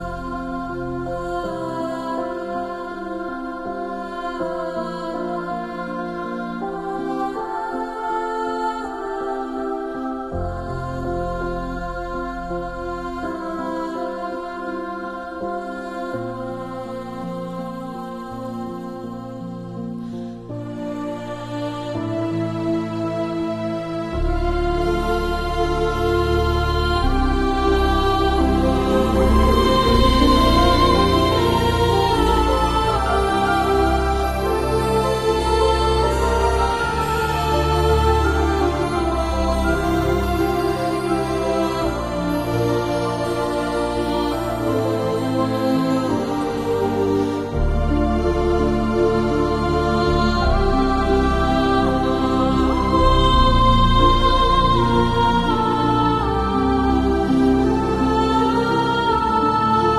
Titanic Inmersive ASMR | PT2 sound effects free download